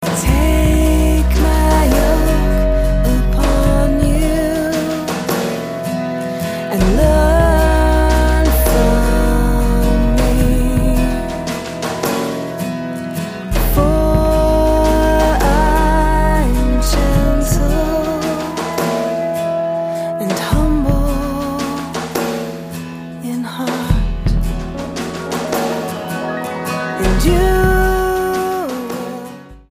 STYLE: Roots/Acoustic
a very restful arrangement to start with.